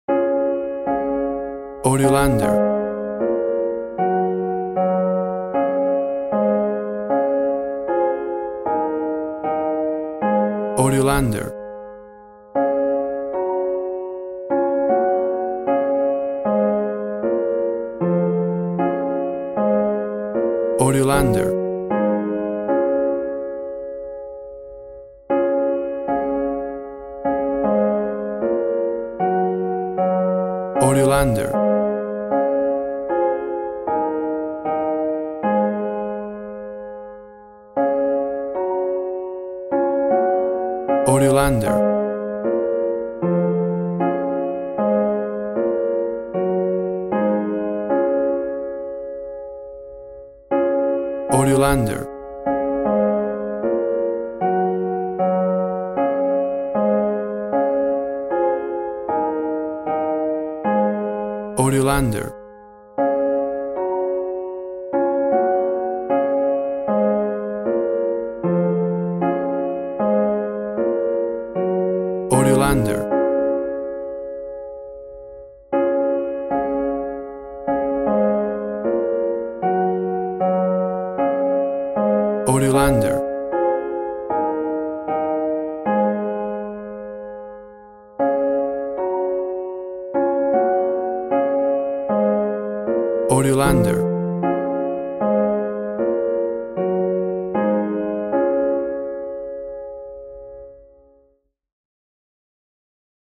A soft and smooth version of the well know christmas tune
played on a beautiful acoustic piano
WAV Sample Rate 16-Bit Stereo, 44.1 kHz
Tempo (BPM) 80